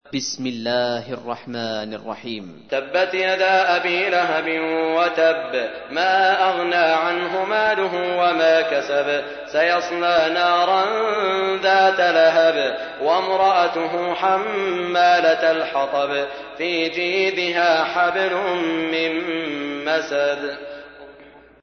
تحميل : 111. سورة المسد / القارئ سعود الشريم / القرآن الكريم / موقع يا حسين